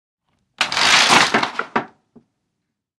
DOORS/STRUCTURES WINDOWS: Roller blinds, pull & flap.